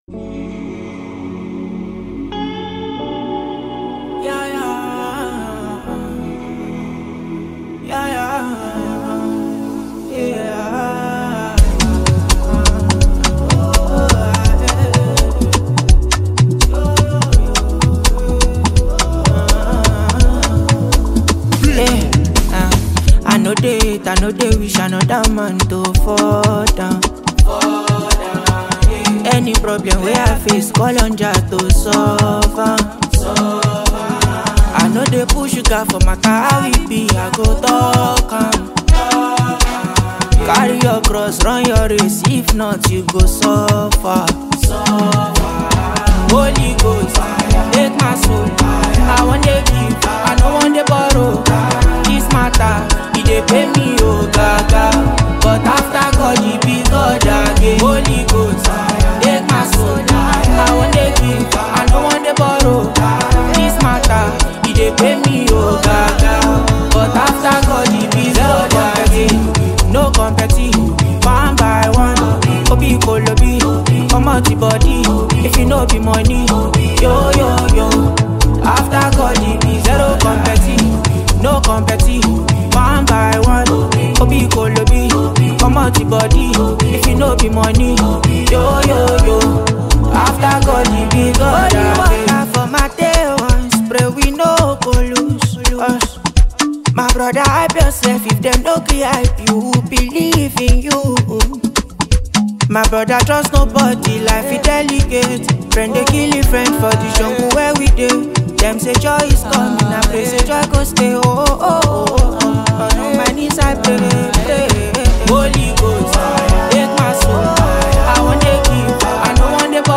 With its infectious beat and heartfelt lyrics
Afro-influenced gospel music